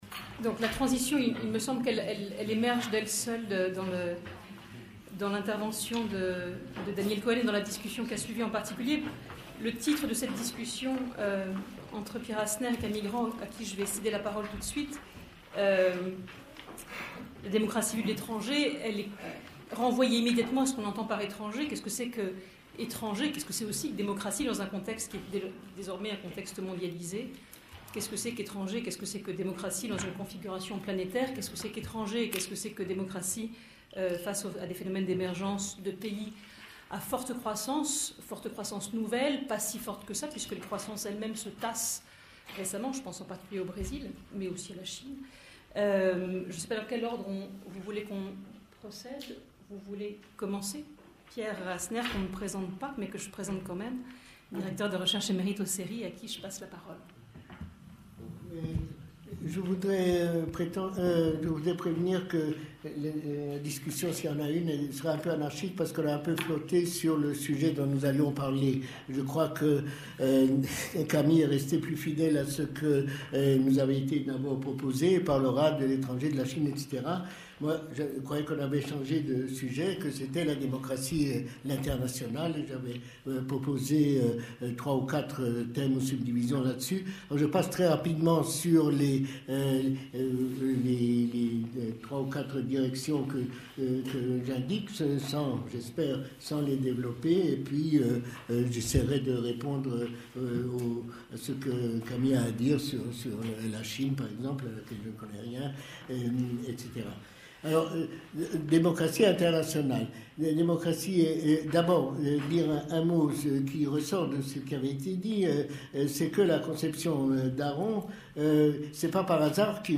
Démocratie : histoire, combats, critiques. Journée d'étude organisée par le CRESPA : dialogues en guise de conclusion.